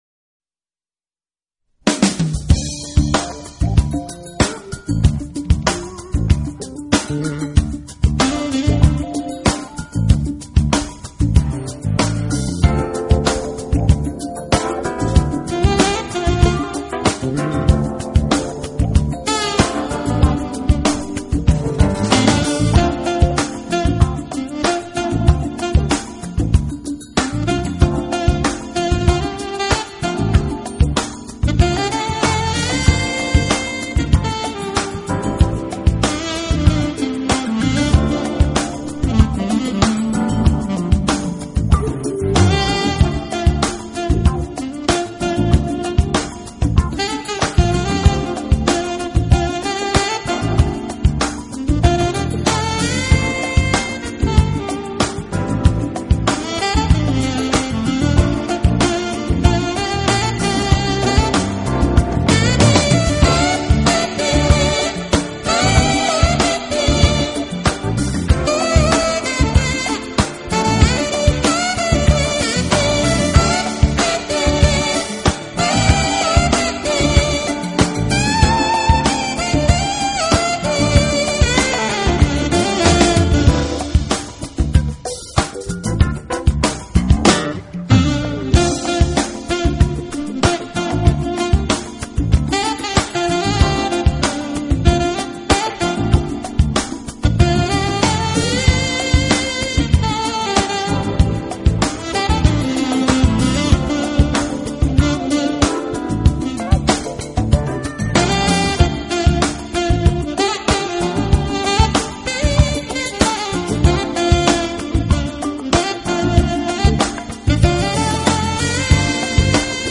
Genre: Jazz
Styles: Instrumental Pop Crossover Jazz